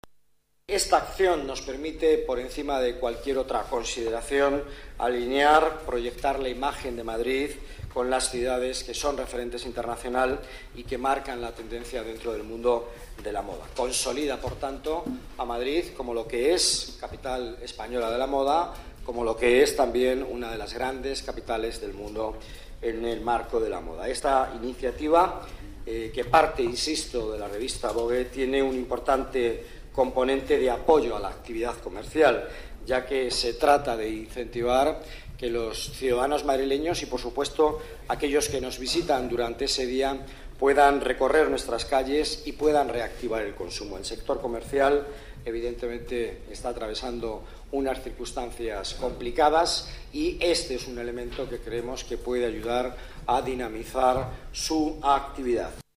Nueva ventana:Declaraciones del delegado de Economía y Participación Ciudadana, Miguel Ángel Villanueva: Beneficios de la Noche de la Moda para la Ciudad de Madrid